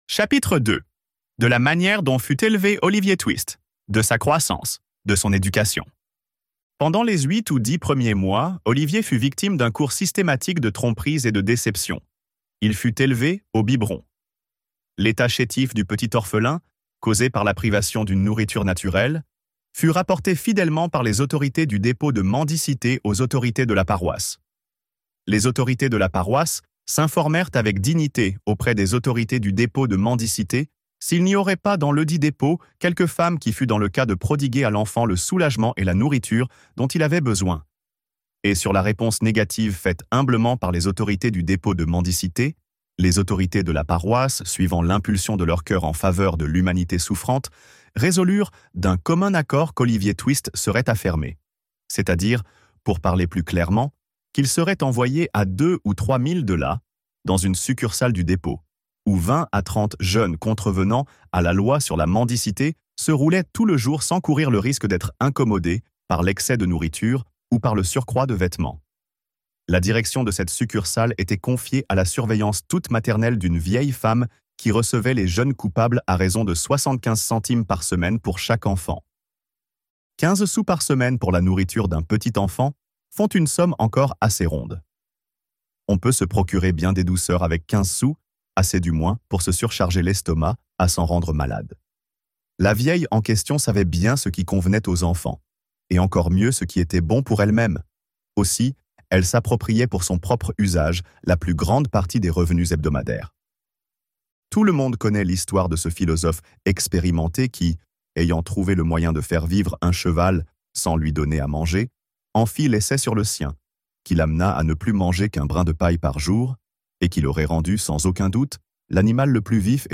Olivier Twist - Livre Audio